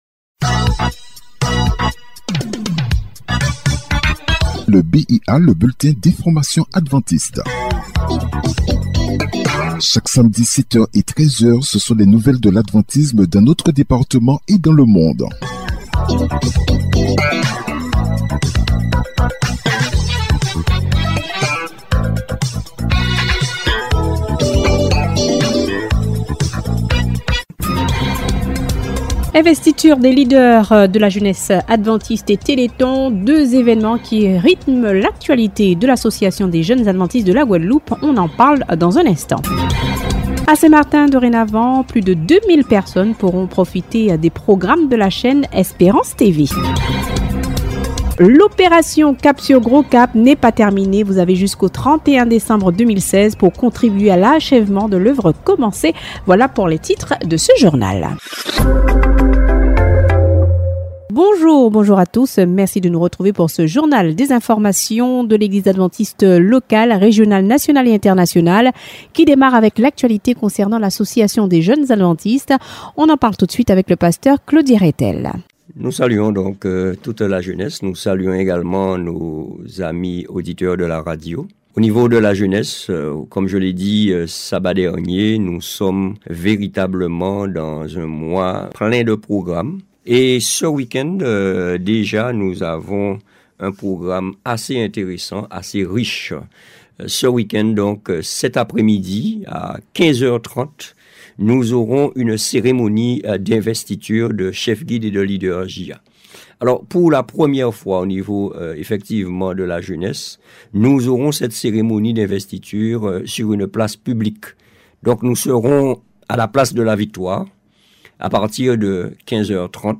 Le journal peut être écouté directement ou il peut être téléchargé.